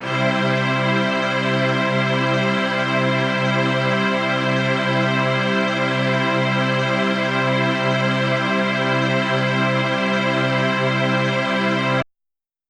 SO_KTron-Ensemble-Cmaj.wav